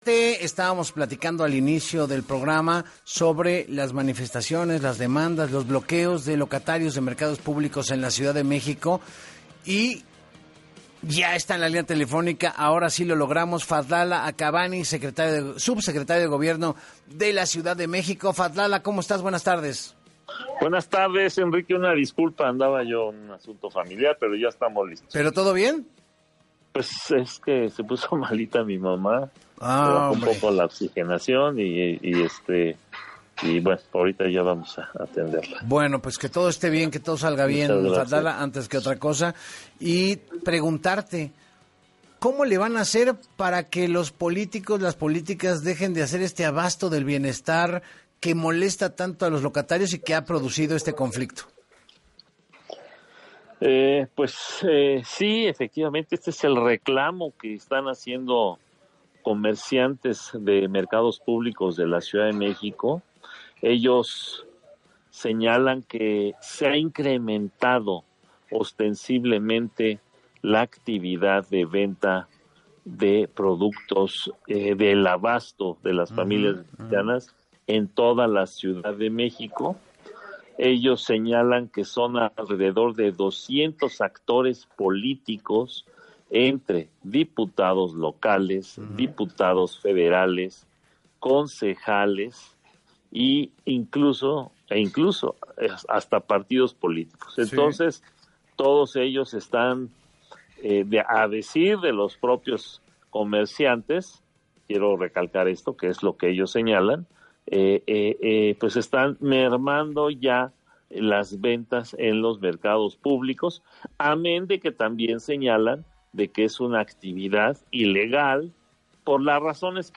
En entrevista con Enrique Hernández Alcázar, el subsecretario de Gobierno de la Ciudad de México, Fadlala Akabani, dijo que los comerciantes “alegan” que productos son prebendas políticas y que por parte del Gobierno de la capital harán una revisión legal porque diputados no tienen permiso de vender en la vía pública e investigarán en qué partes y puntos realizan esta actividad.